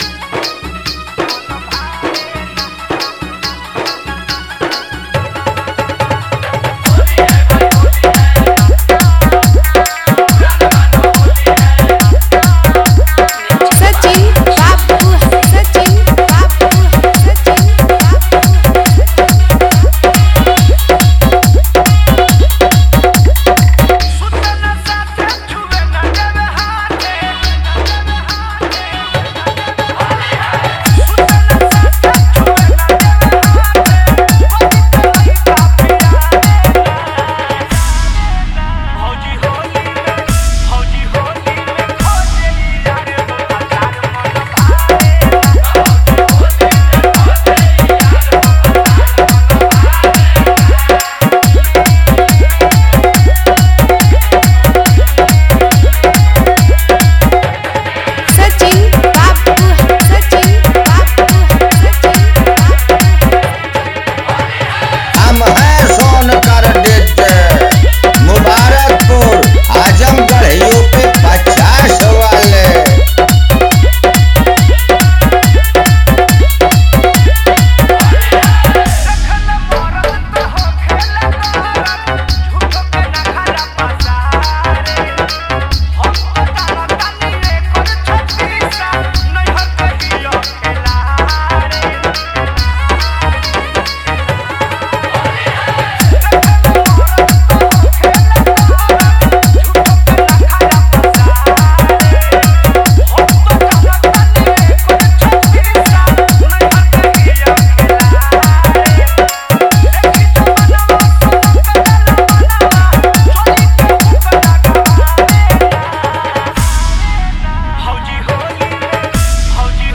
Holi Dj Remix Song Download